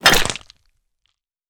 SFX_trash01.wav